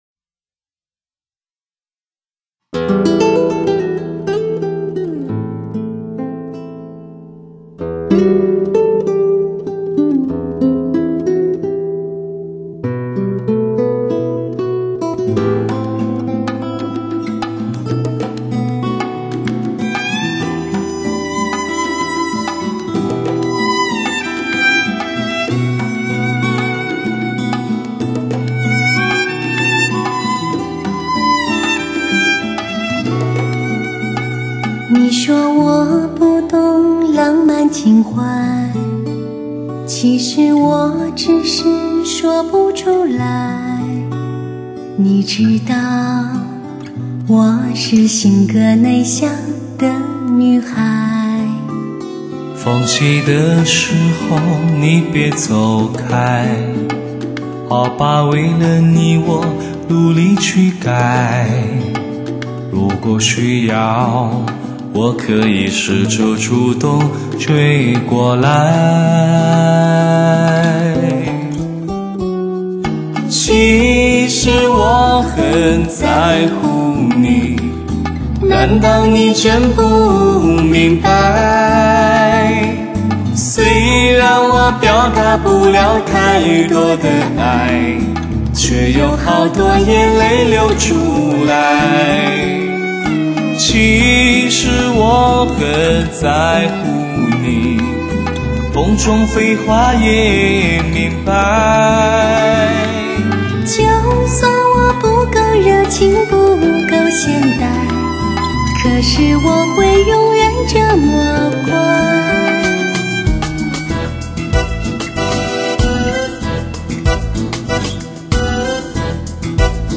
国际音响协会多声道音乐录音典范，1:1德国母盘直刻技术，
塑造无比传真的高临场音效，极富视听效果的发烧靓声，
两个水融的人声，点燃你最真切的共鸣......